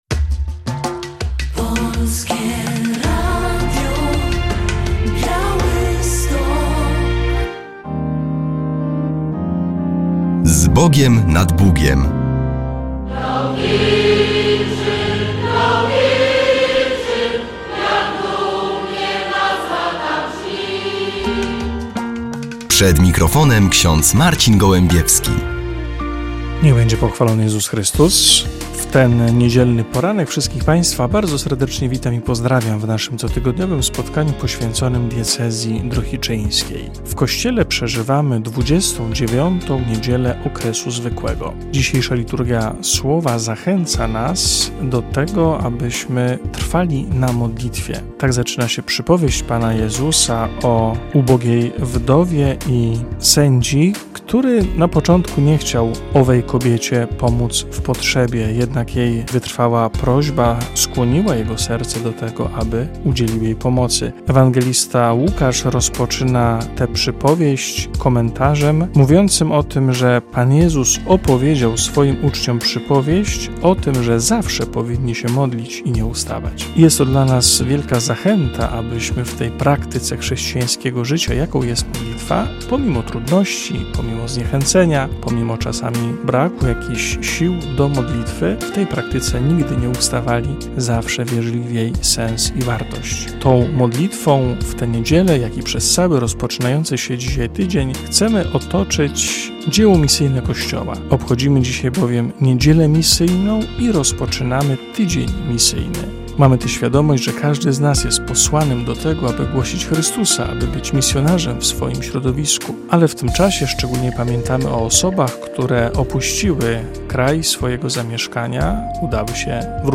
W audycji relacja z pobłogosławienia grobu na cmentarzu parafialnym w Sokołowie Podlaskim i pochówku dzieci utraconych.